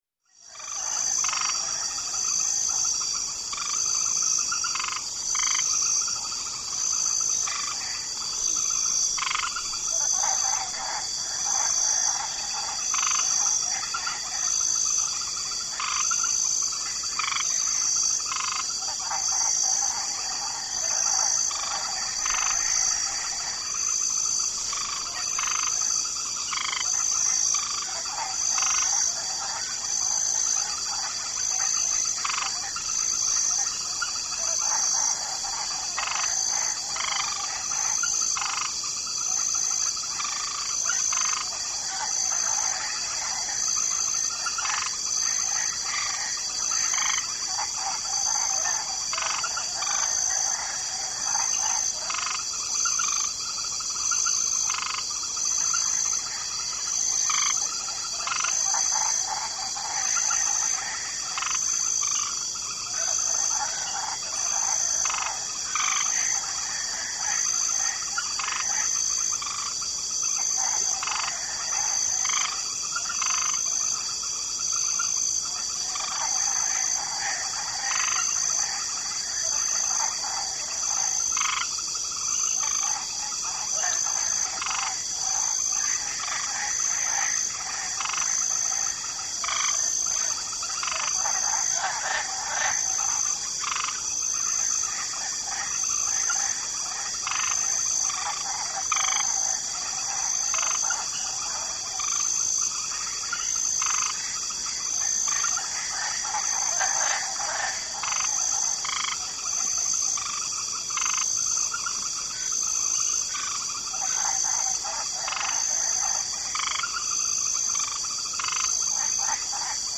Swamp Ambience 2 [special Mix],; Light Chorus Of Frogs Croak, Distant Perspective, With Insects Lazy Chirps, Medium Distant Perspective.